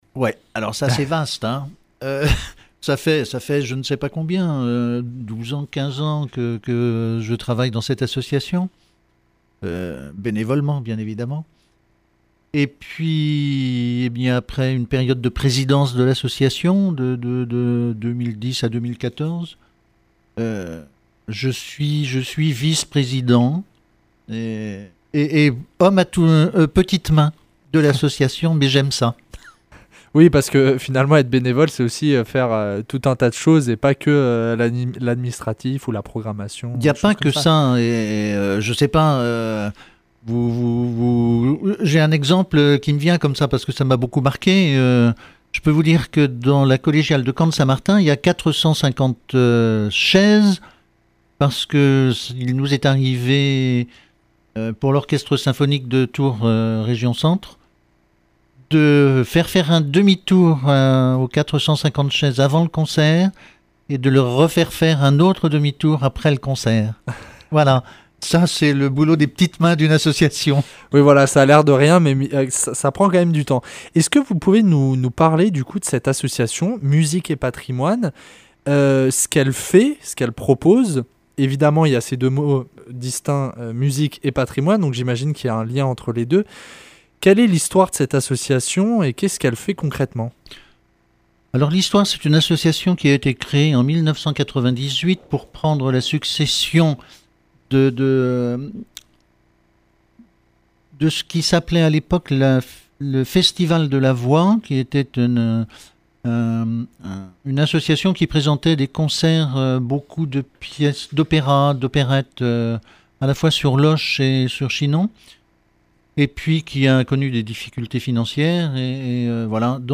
Au programme de l’émission ? De la musique, mais classique ! Interview.